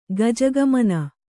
♪ gajagamana